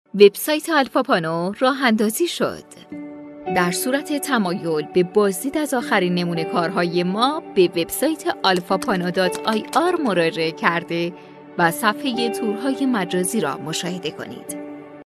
Female
Young